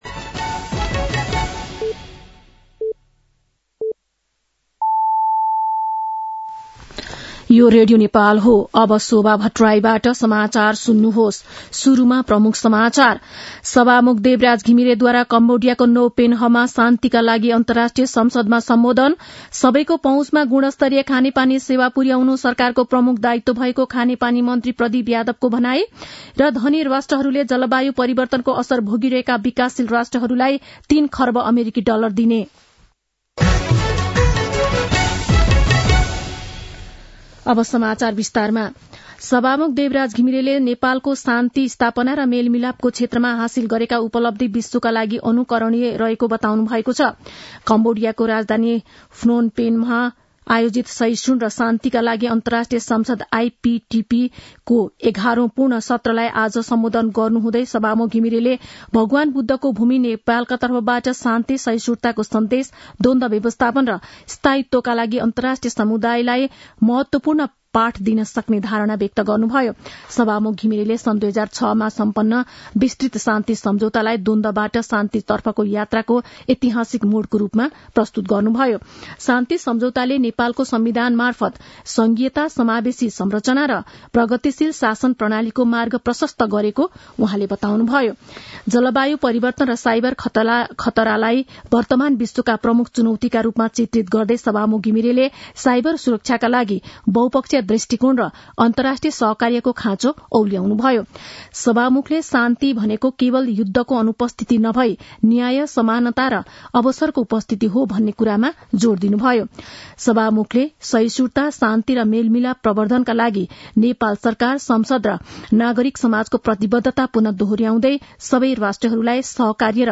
दिउँसो ३ बजेको नेपाली समाचार : १० मंसिर , २०८१
3-pm-Nepali-News-3.mp3